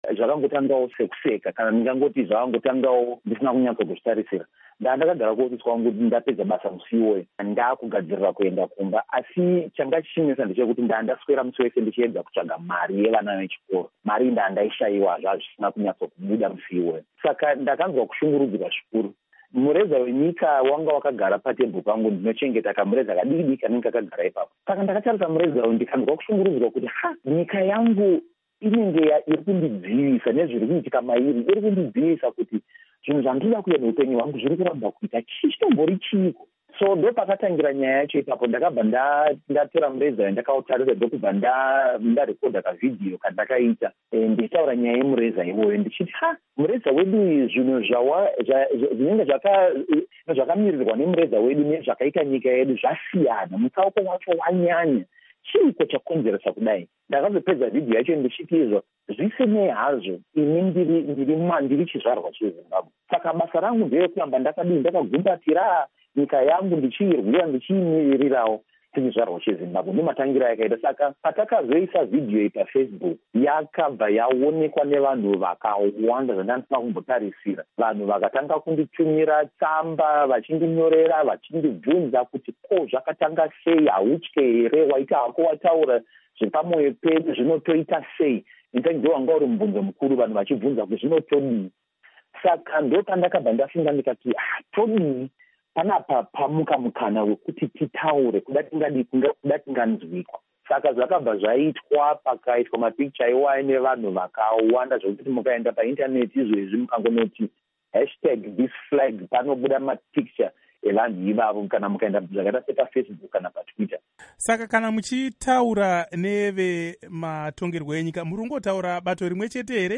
Hurukuro naPastor Evan Mawire